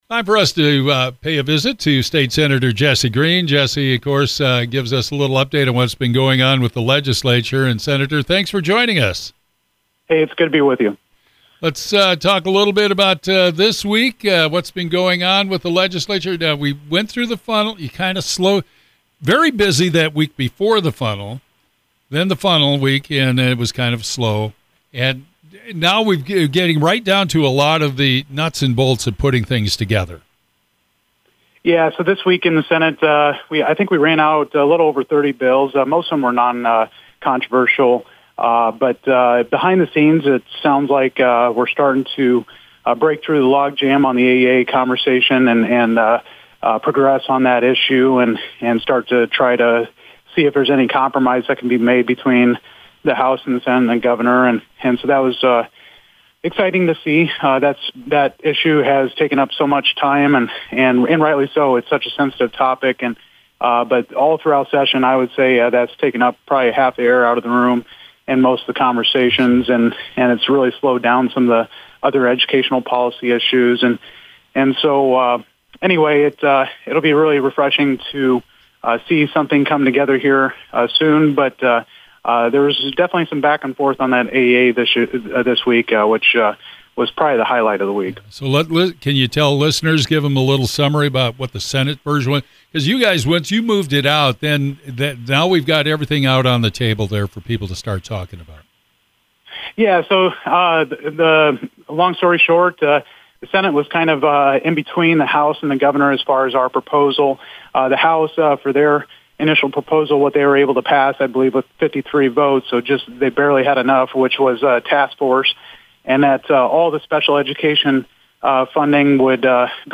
State Senator Jesse Green, Legislative Review. Green talks about the past week of the legislative session and some of the things that moved on the Senate side of the aisle. He talks about some of the differences in the AEA proposals for the Senate and House. He also talks about some of the budget expectations.